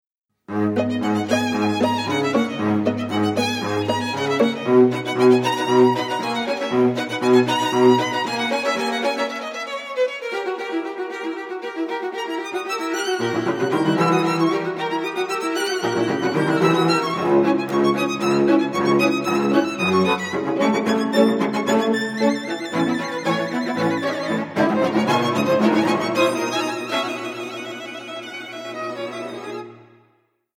Klasická hudba